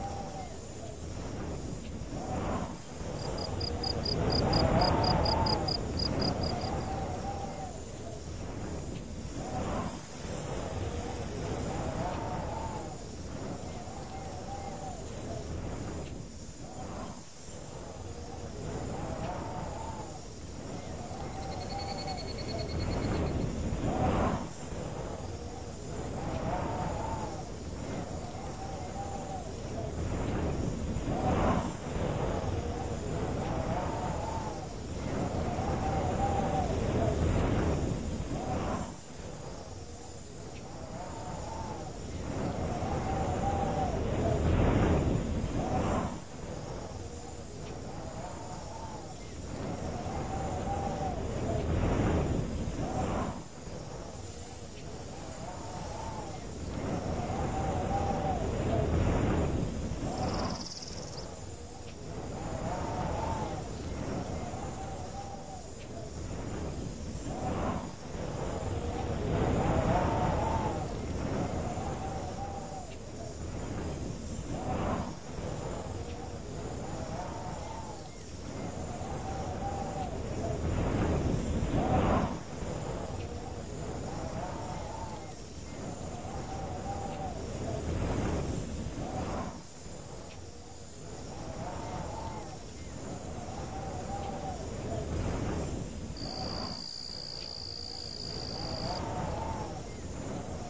Nature sounds are recorded & designed to help people sleep, allowing you to relax and enjoy the sounds of nature while you rest or focus.
Perfect for their masking effects, they are also helpful for people suffering with tinnitus.